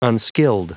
Prononciation du mot unskilled en anglais (fichier audio)
Prononciation du mot : unskilled